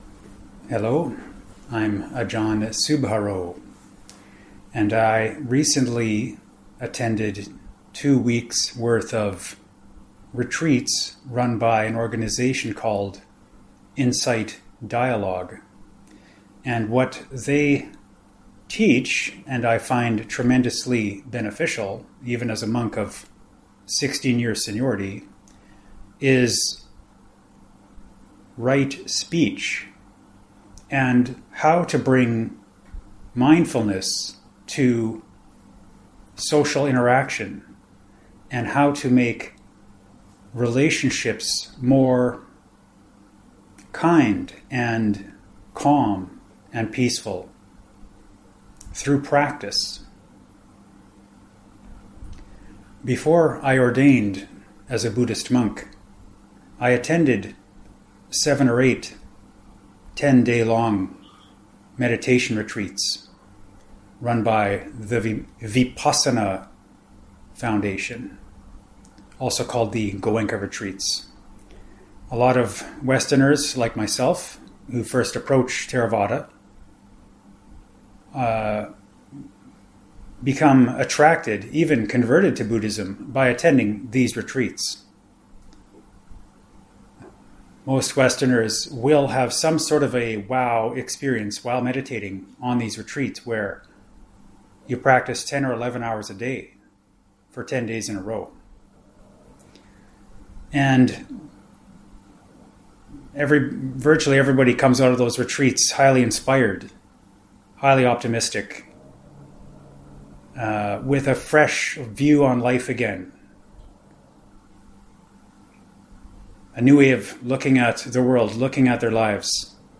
Here is a 20-min recorded audio-only Dhamma Talk, recorded while visiting BGF, Kuala Lumpur - downloadable 15MB .mp3 (Tip: tap and hold to “Download link”, or right click to “Save Link As…“).